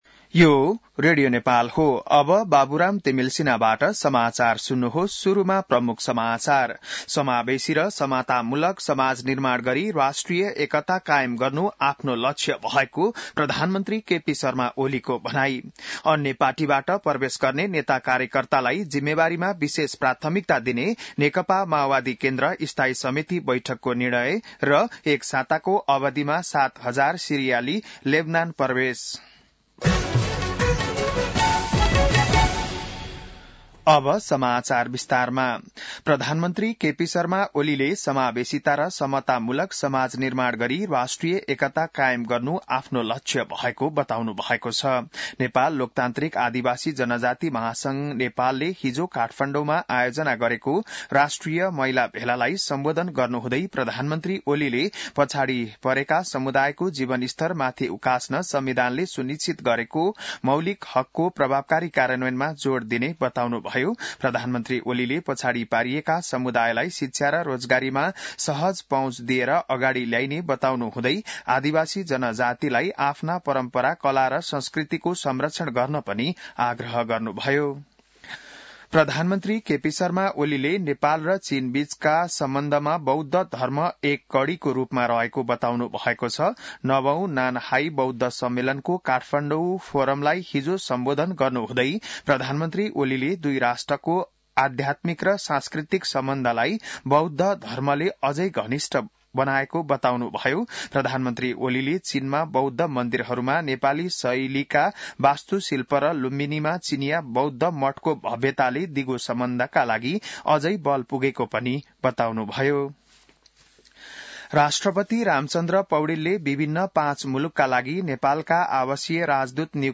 बिहान ९ बजेको नेपाली समाचार : ३० मंसिर , २०८१